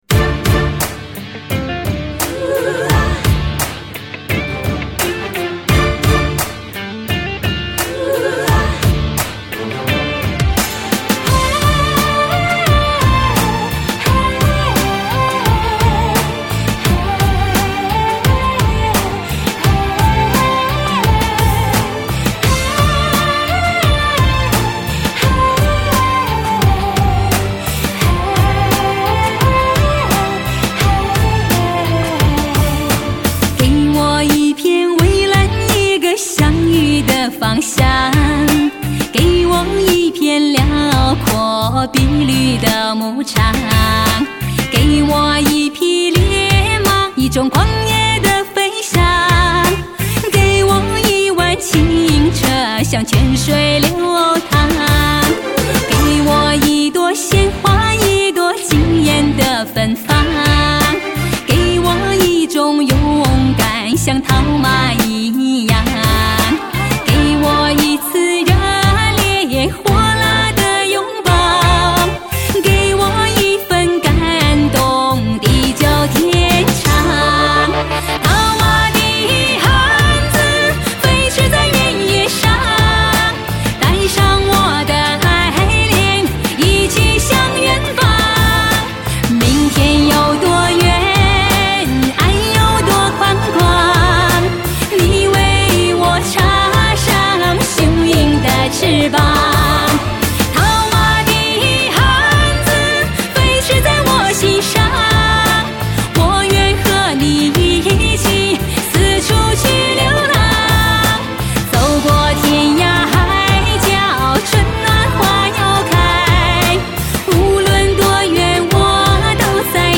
美丽天堂，马头琴声在流淌；